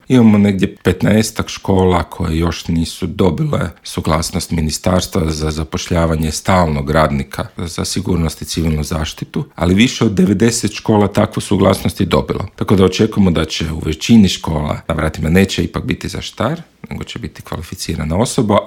U novu pedagošku godinu Grad Zagreb je krenuo s dosad najboljom situacijom, ustvrdio je pročelnik gradskog ureda za obrazovanje, sport i mlade Luka Juroš: